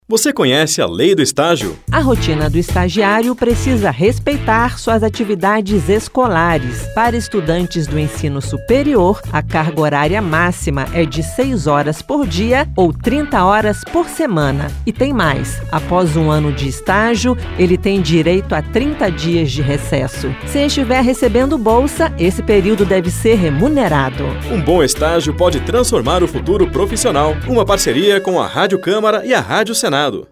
Esta campanha da Rádio Câmara e da Rádio Senado traz cinco spots de 30 segundos sobre a Lei do Estágio: seus direitos, obrigações e os principais pontos da lei.